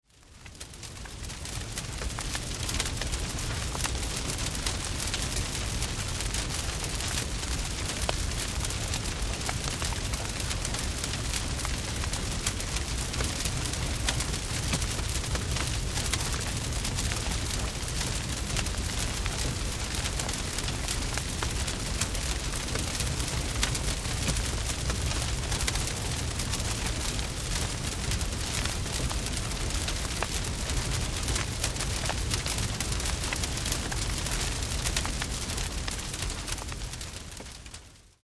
6 Stunden Kaminknistern / Kaminfeuergeräusche
Bei Geräuschaufnahmen sind diese ebenfalls in 44.1 kHz Stereo aufgenommen, allerdings etwas leister auf -23 LUFS gemastert.
44.1 kHz / Stereo Sound
Lautstärke: -23 LUFS
Hoerprobe-Kamin.mp3